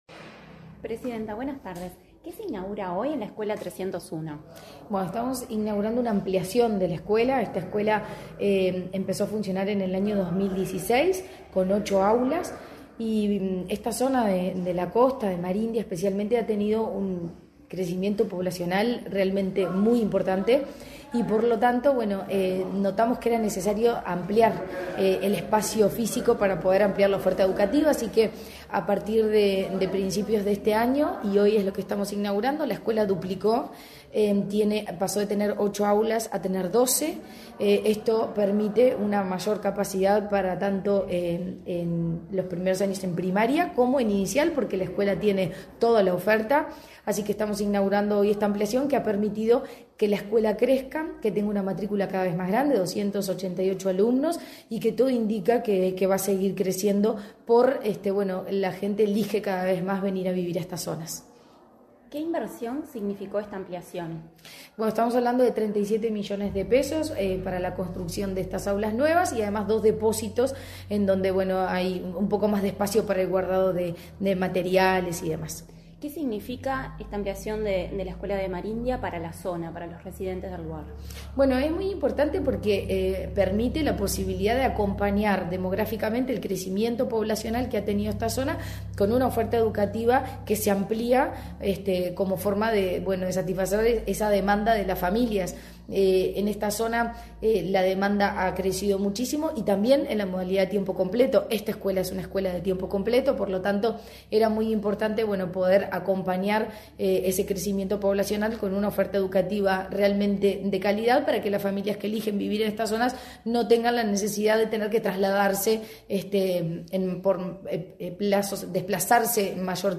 Entrevista a la presidenta de la ANEP, Virginia Cáceres
Tras participar en la inauguración de la ampliación y remodelación de la Escuela N.° 301 de tiempo completo de Marindia, en el departamento de